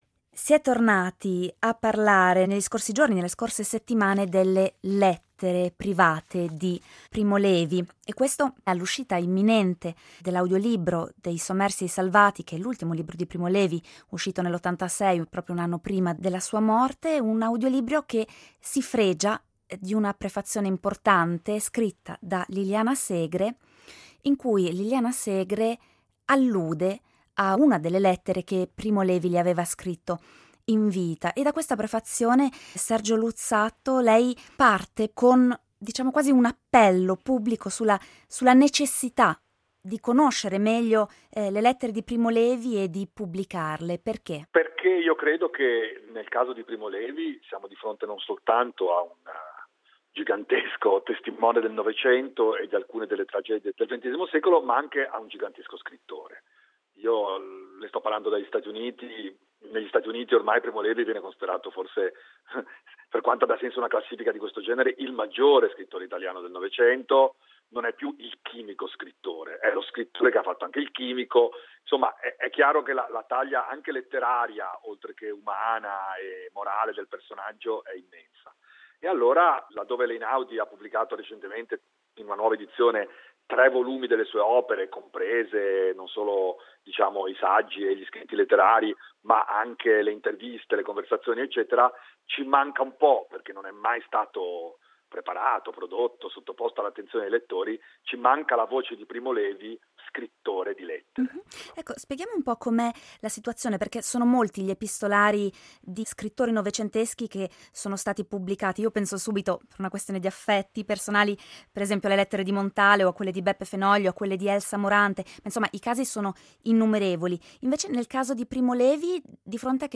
Torniamo a parlare del grande scrittore italiano: in particolare delle lettere private che lo scrittore inviò in vita ad amici, conoscenti, ma anche ai suoi lettori e addirittura ai suoi oppositori. Queste lettere secondo lo storico Sergio Luzzatto andrebbero pubblicate.